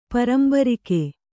♪ parambarike